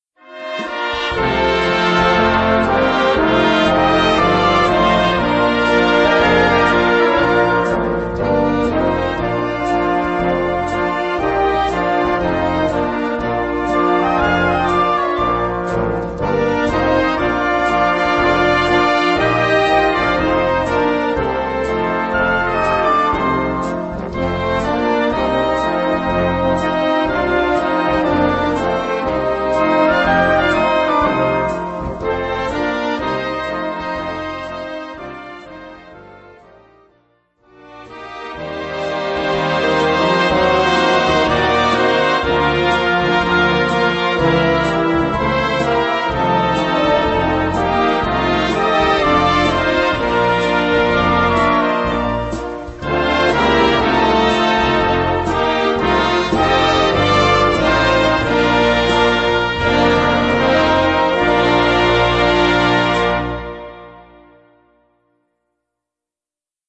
Gattung: Slow Fox
Besetzung: Blasorchester